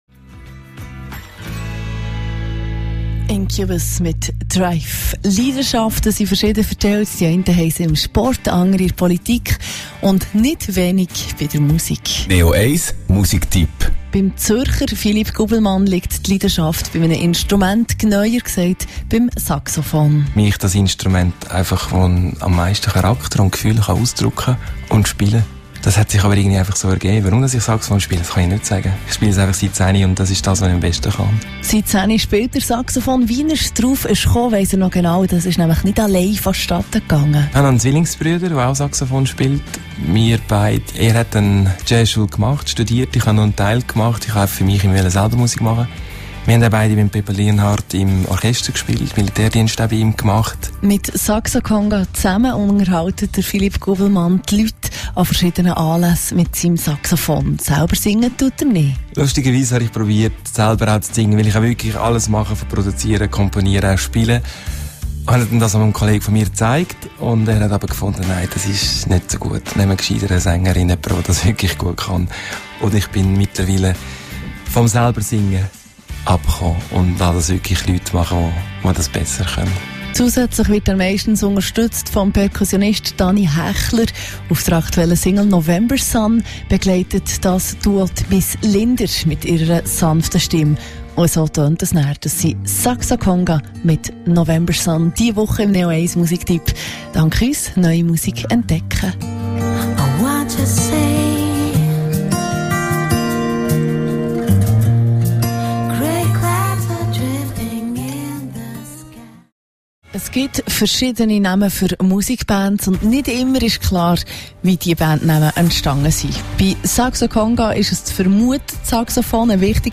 Interview Radio neo1 (mp3)